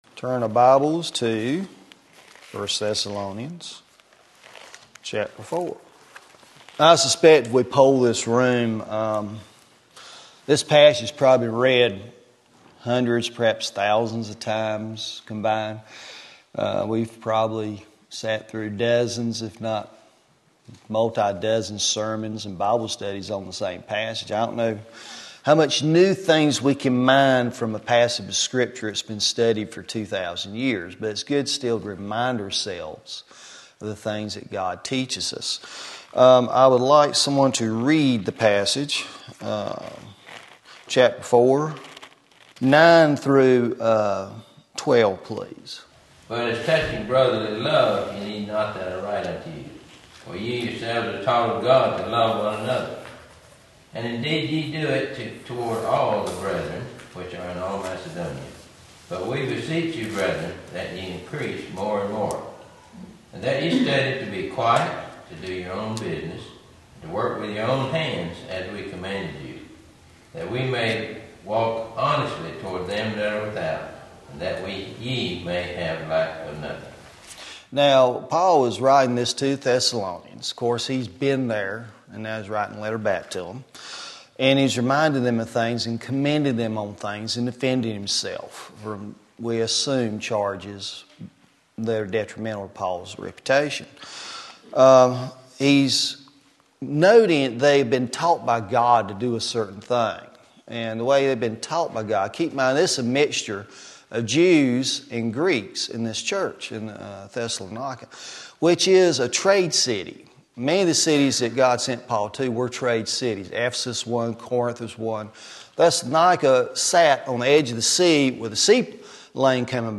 Audience comments galore ensued. menu email link file_download